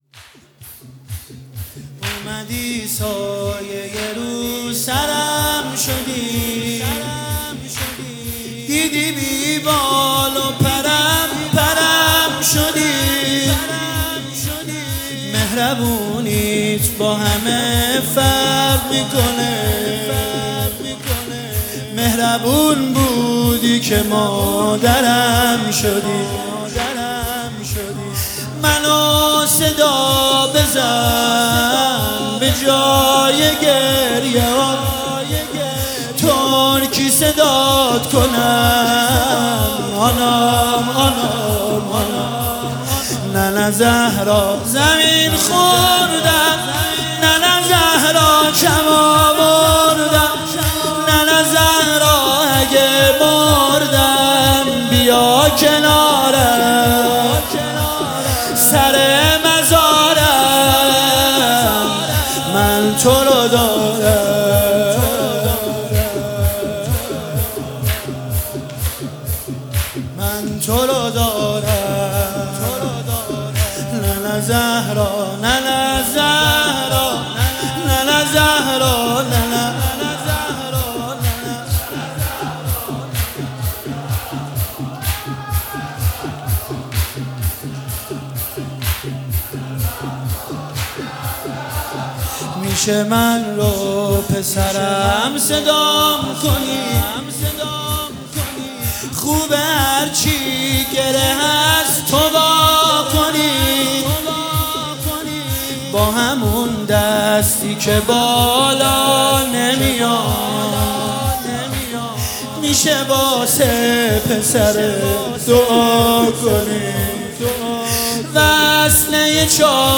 مداحی_شهادت حضرت زهرا